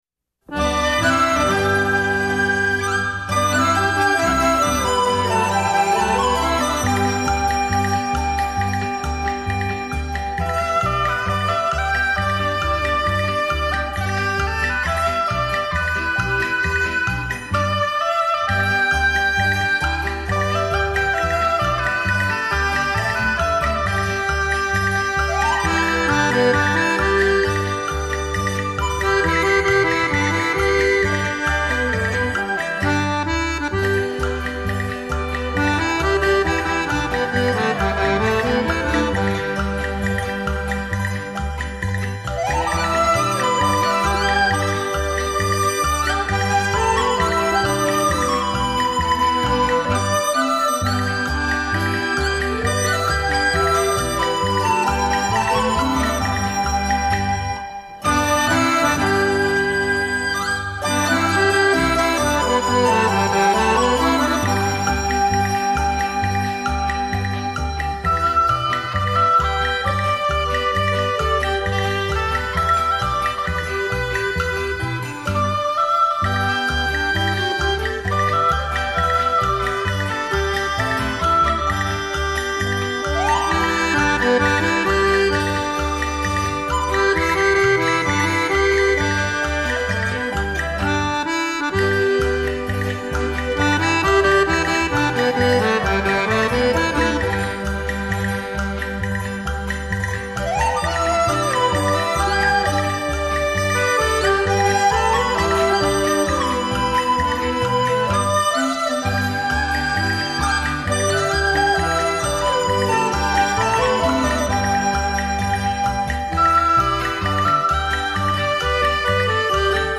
音乐类型: 民乐
古老的乐器和现代流行音乐完美的结合演绎
出清雅动人、潺潺流水般的优美旋律，使人